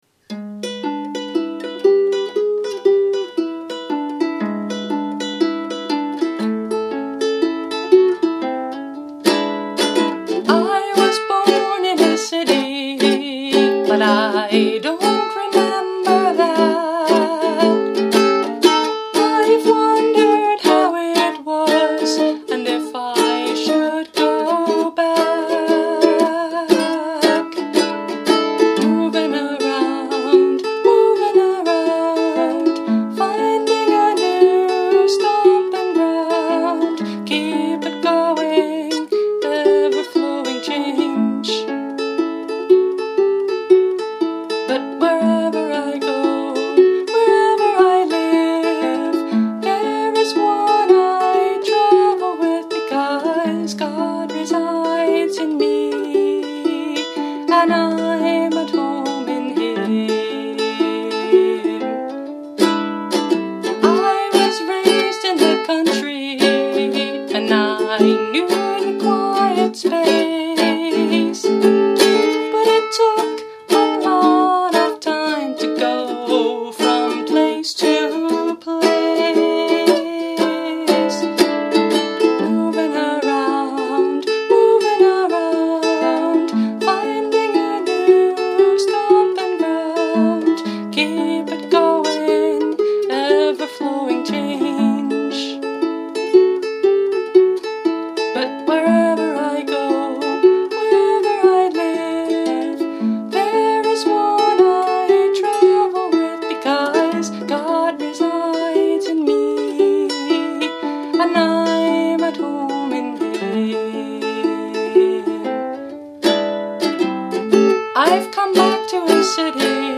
Instrument: Eventide – Mahogany Concert Ukulele